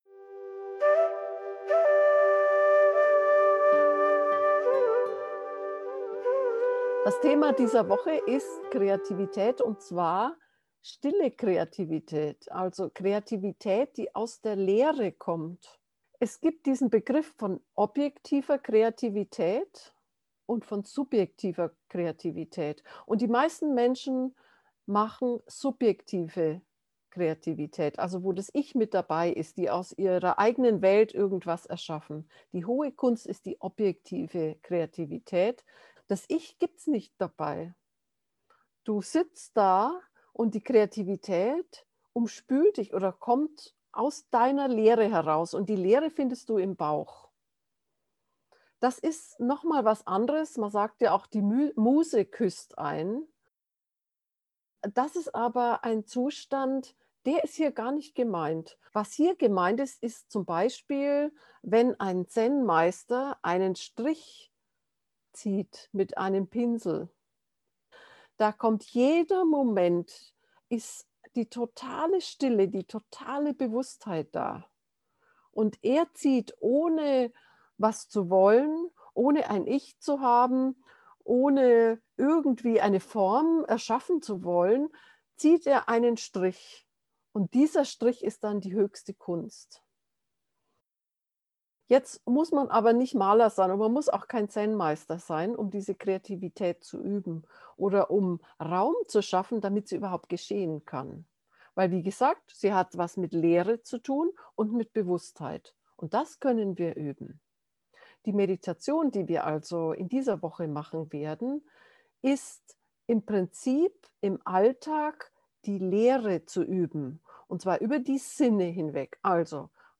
objektive-kreativitaet-gefuehrte-meditation